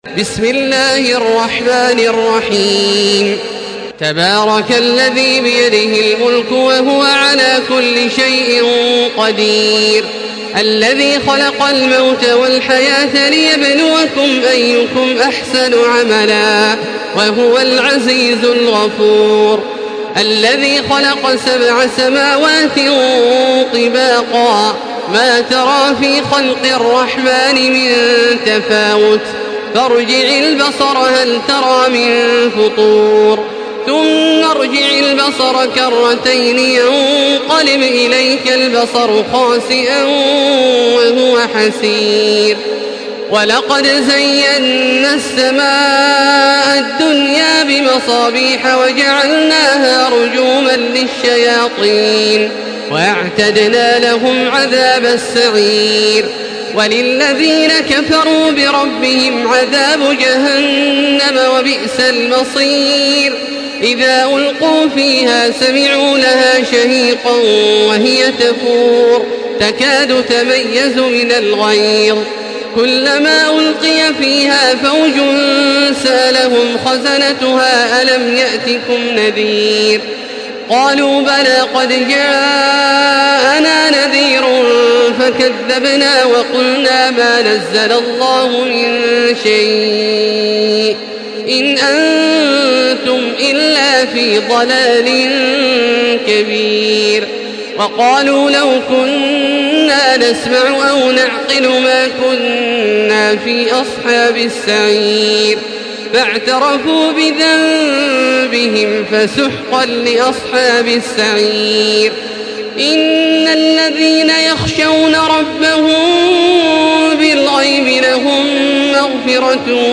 Une récitation touchante et belle des versets coraniques par la narration Hafs An Asim.
Makkah Taraweeh 1435
Murattal